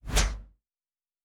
Synth Whoosh 3_4.wav